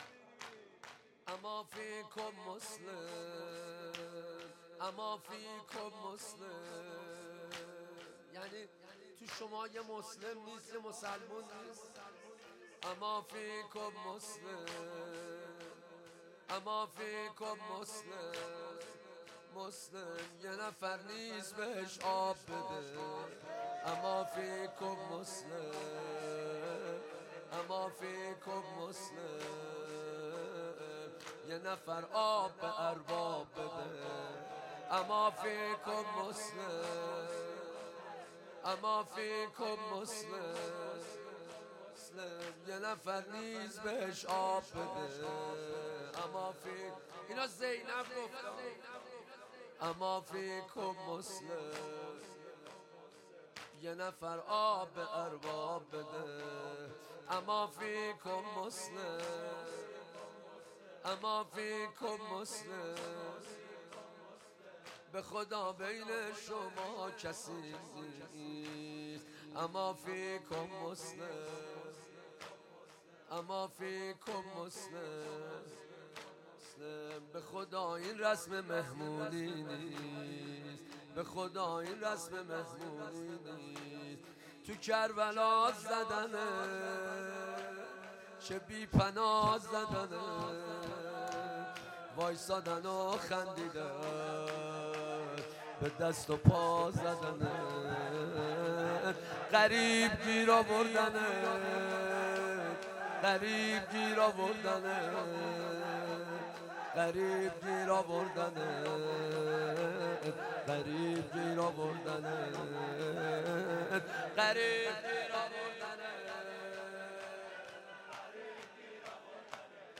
تک/عربی